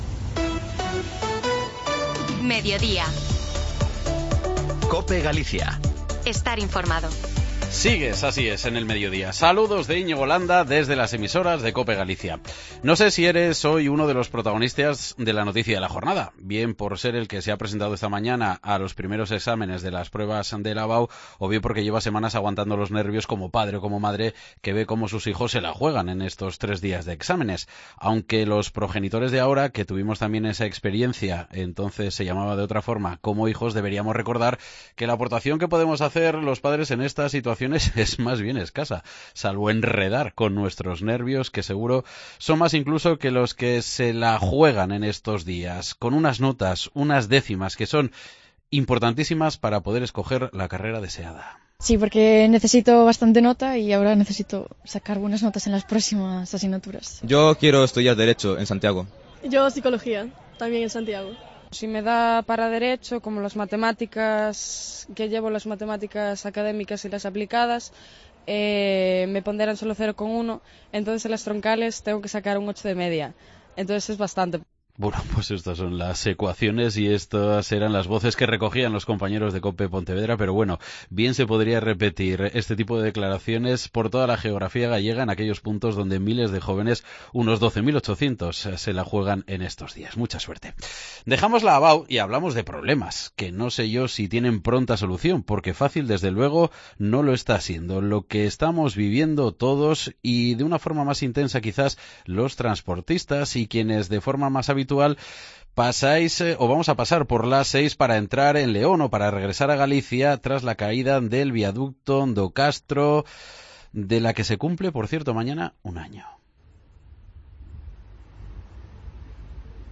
Se cumple este 7 de junio un año del derrumbe de uno de los viaductos do Castro de la A-6 en la frontera entre León y Galicia al que le siguió el colapso de segundo 10 días más tarde, y desde la Xunta aseguran que es imposible que esté lista la reconstrucción de ambos a finales de 2024 como se ha comprometido el Ministerio de Transportes. Hasta Vega de Valcarce se ha desplazado este martes la Conselleira de Infraestructuras, Ethel Vázquez, para reunirse con los transportistas y con ella hemos hablado en el Mediodía Cope Galicia.